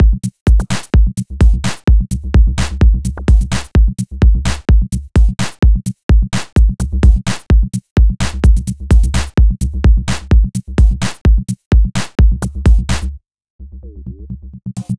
• Rhythm Electro Drum Machine House Minimal - Cm - 128.wav
Rhythm_Electro_Drum_Machine_House_Minimal_-_Cm_-_128_Snf.wav